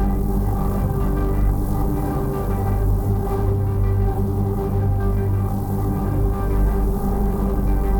Index of /musicradar/dystopian-drone-samples/Tempo Loops/90bpm
DD_TempoDroneC_90-G.wav